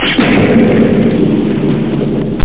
MISSILE.mp3